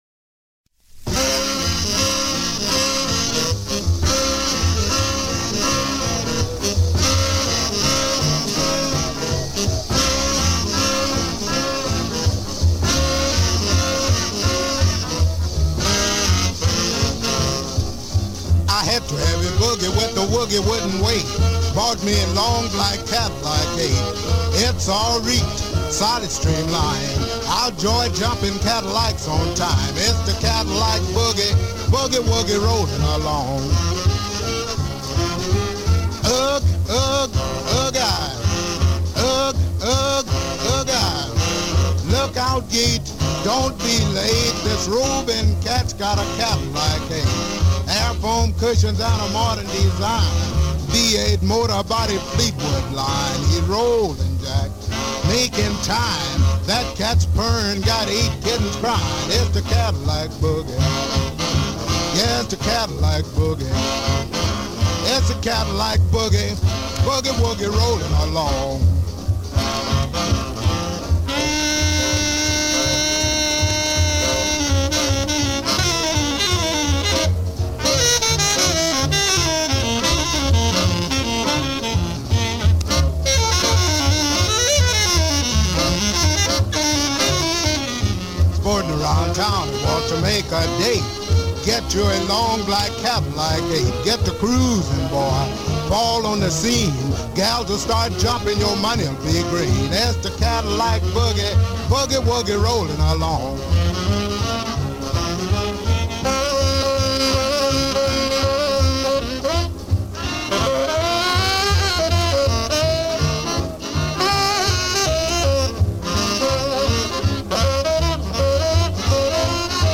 guitar & vocals
tenor sax
alto sax
trumpet
piano
bass
drums